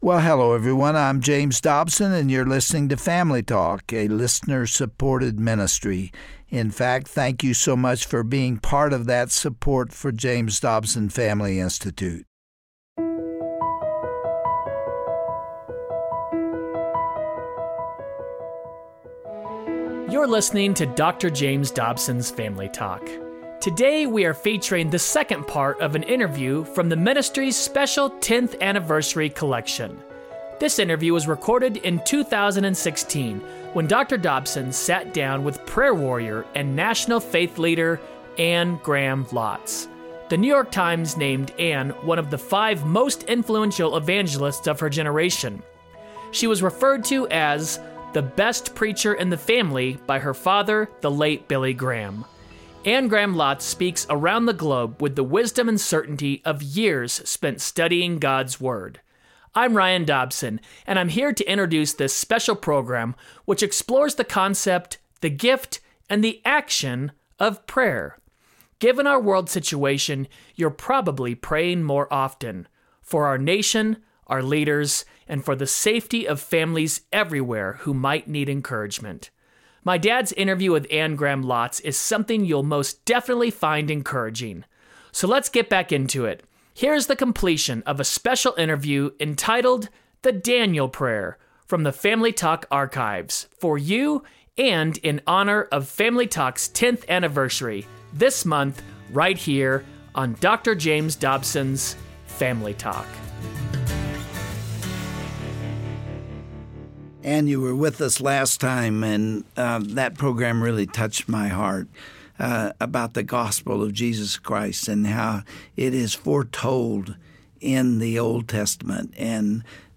Today Family Talk continues revisiting Dr. Dobsons classic conversation with author and evangelist, Anne Graham Lotz. She explains why the battle for Americas soul is a spiritual one, and challenges Christians to daily be on their knees for this country.